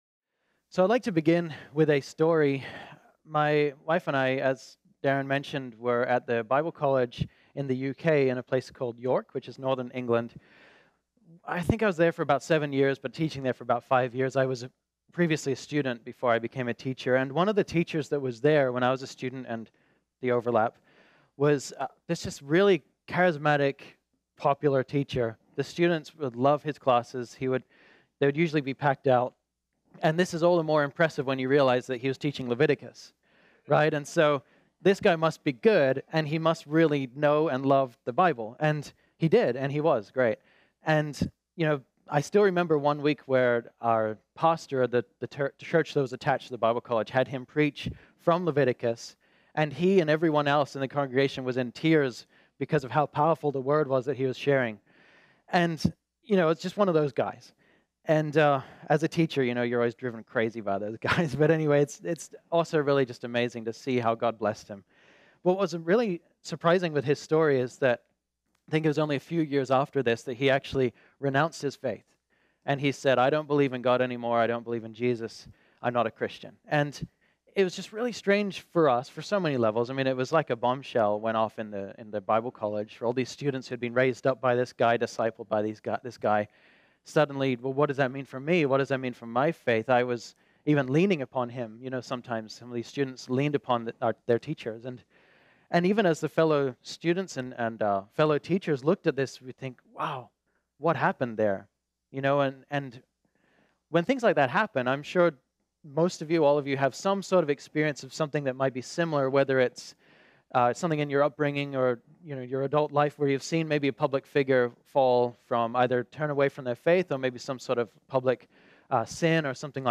This sermon was originally preached on Sunday, July 15, 2018.